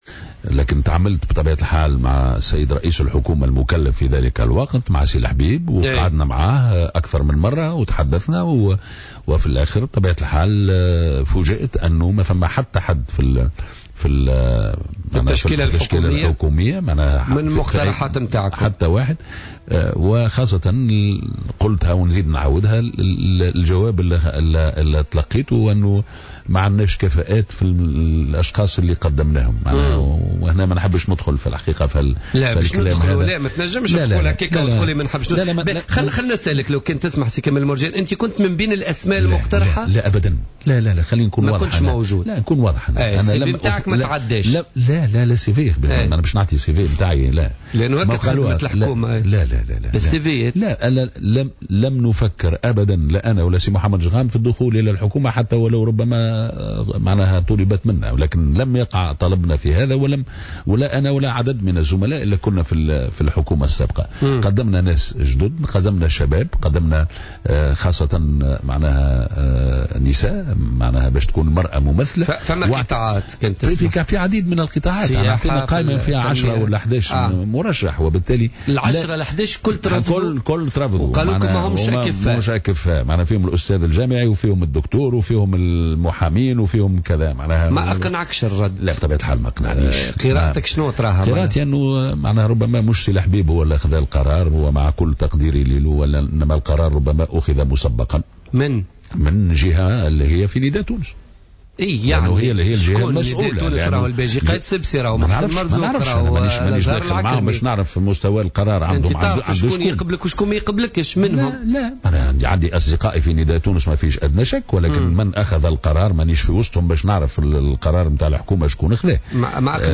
اعتبر رئيس حزب المبادرة الوطنية الدستورية كمال مرجان، ضيف بوليتيكا ليوم الاثنين أن حزبه استحق التواجد بمقعد أو اثنين على الأقل في حكومة الحبيب الصيد، غير أن نداء تونس الذي "غدر" بالمبادرة لم يشأ ذلك.